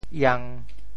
“央”字用潮州话怎么说？
iang1.mp3